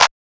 MB Clap 1.wav